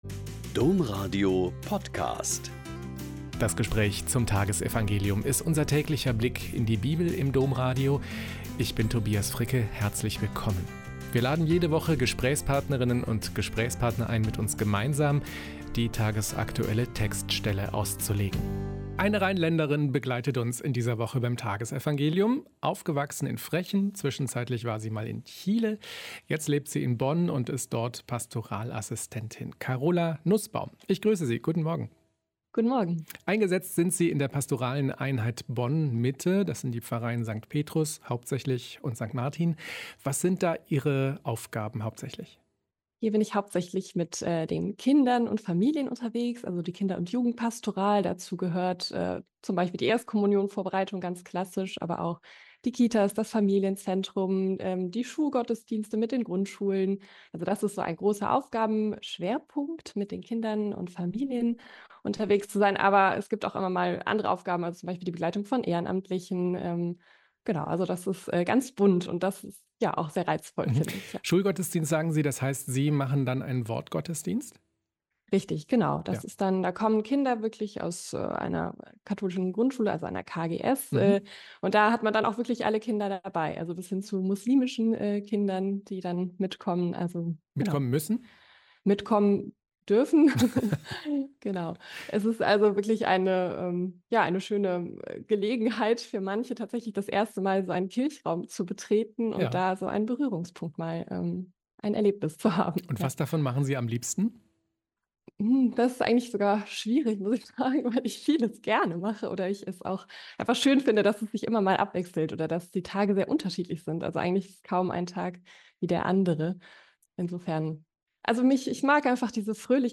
Mk 2,18-22 - Gespräch